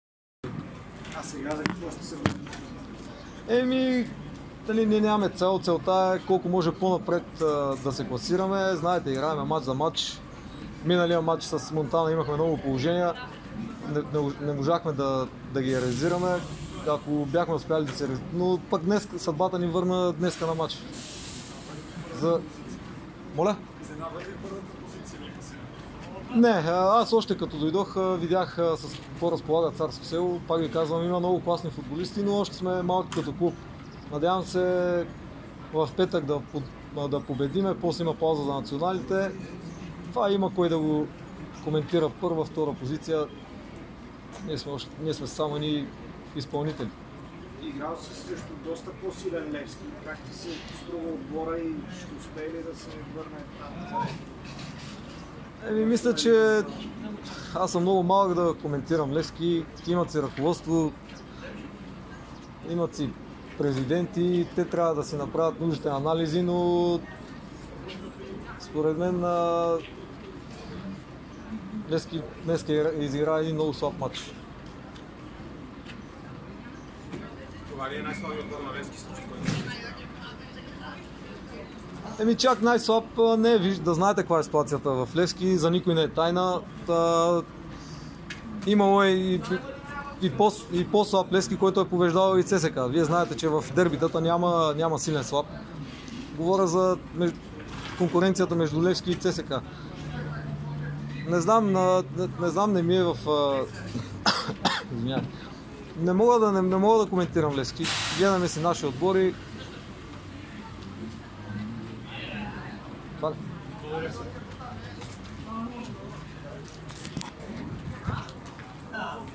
Полузащитникът на Царско село Борис Галчев говори след знаменитата победа на „царете“ с 1:0 срещу Левски на „Герена“. Той призна, че „сините“ са направили много слаб мач, но заяви, че Левски е бил и още по слаб и въпреки това е печелил дербита с ЦСКА.